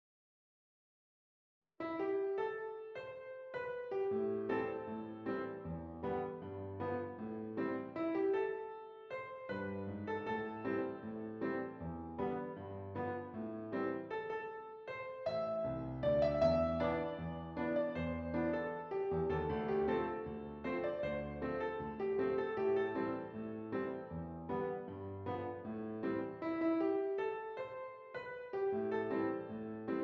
Does Not Contain Lyrics
A Minor
Andante